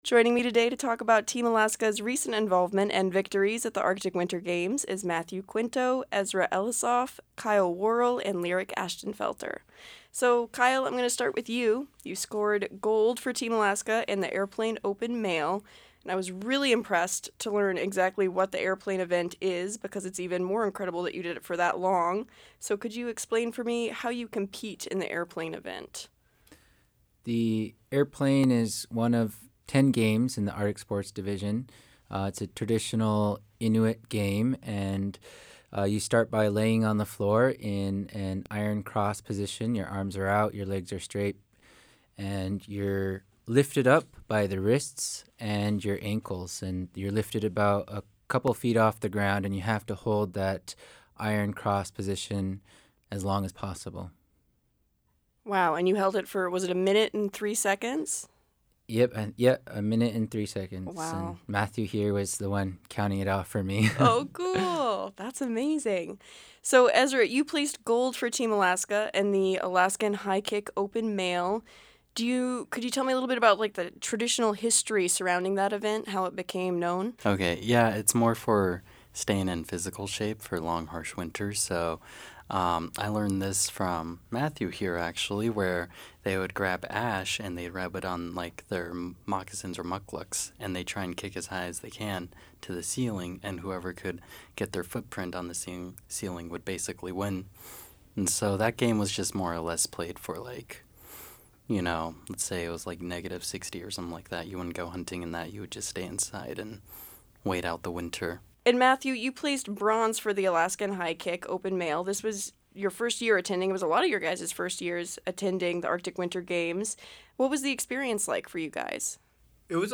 The content of this interview is edited to improve clarity and length.